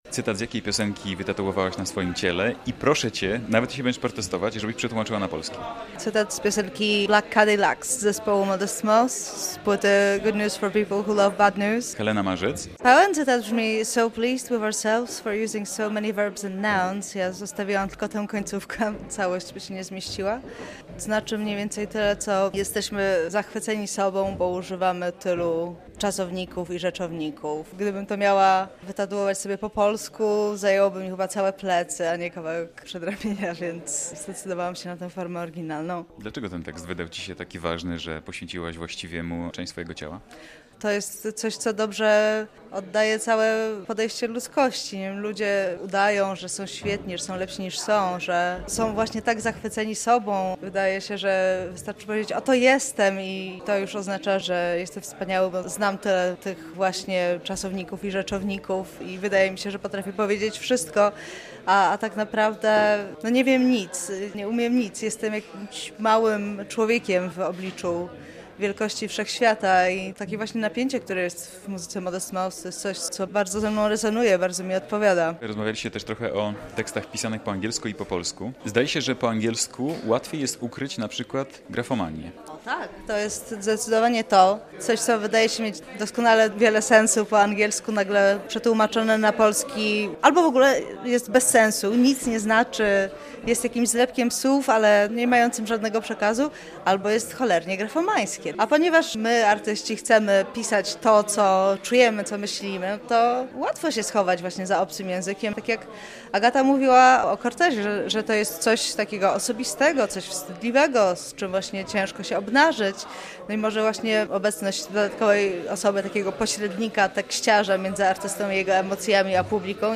Dyskutowano o tym niedawno na festiwalu Halfway w Białymstoku.